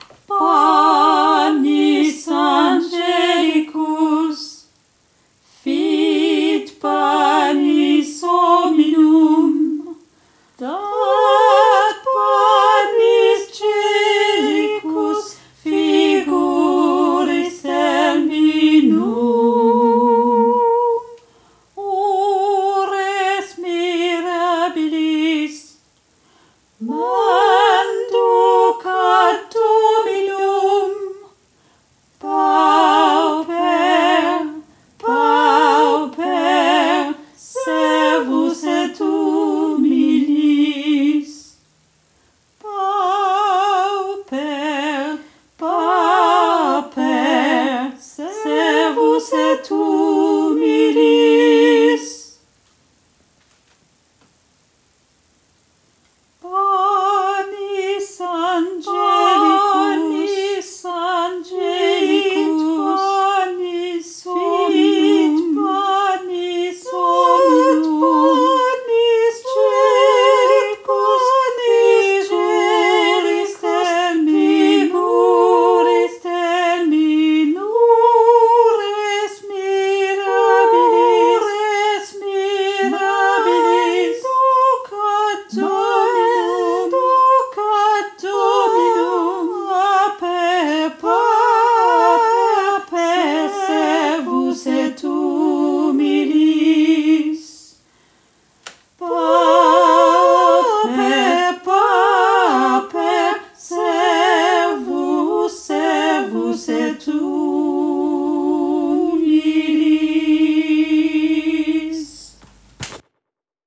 MP3 versions chantées